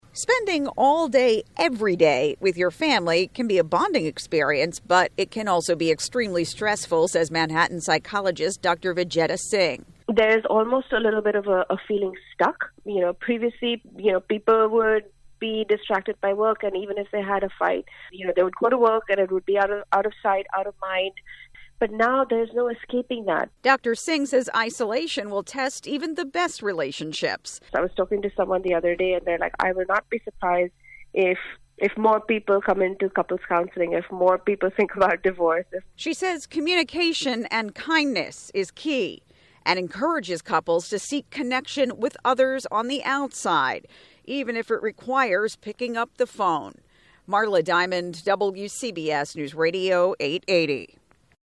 Radio & Podcast